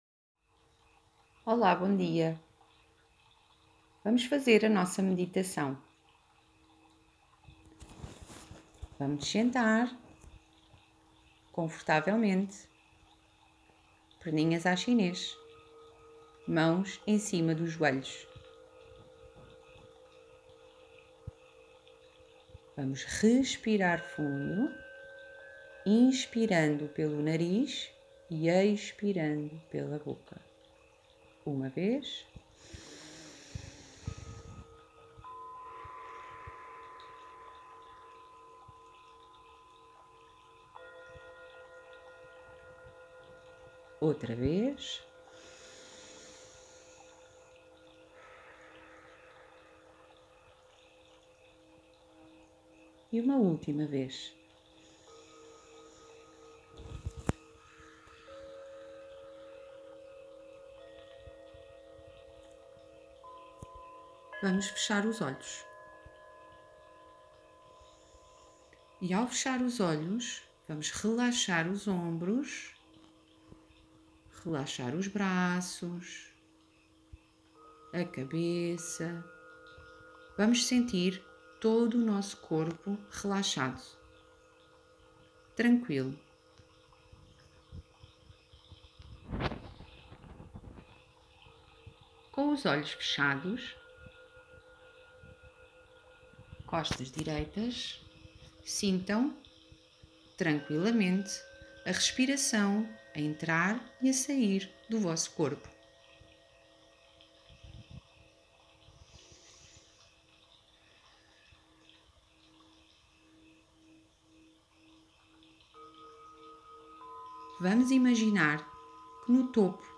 Aula de relaxamento
Ficheiro áudio – meditação